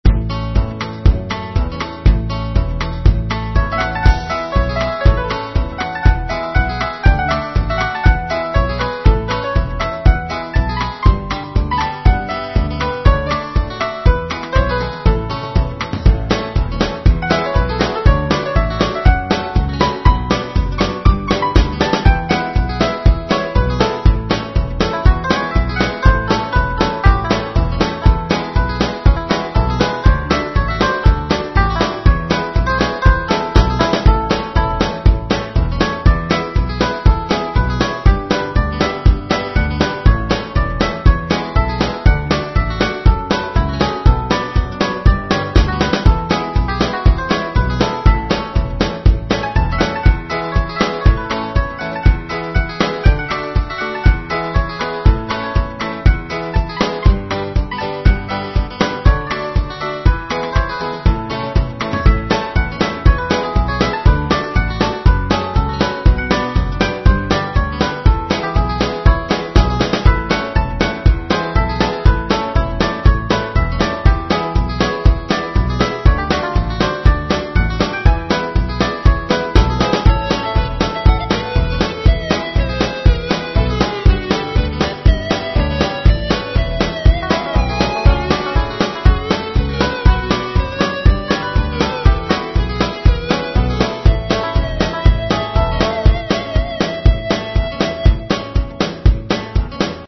Short Playful Country tune